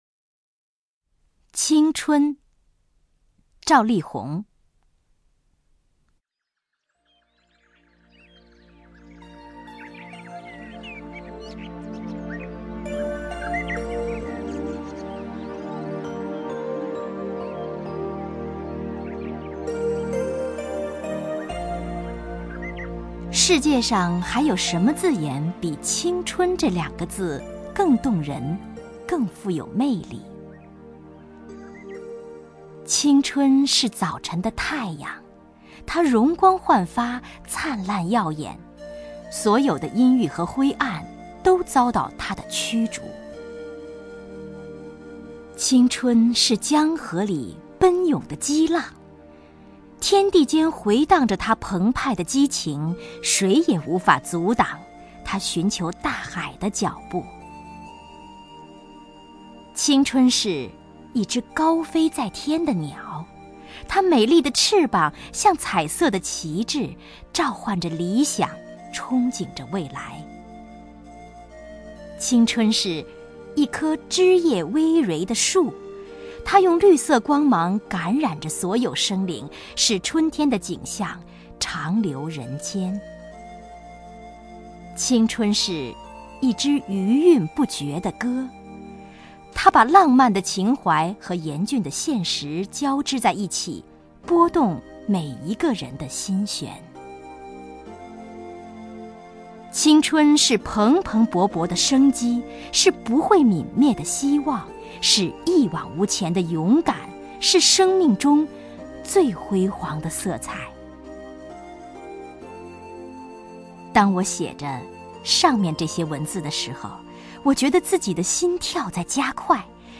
首页 视听 名家朗诵欣赏 王雪纯
王雪纯朗诵：《青春》(赵丽宏)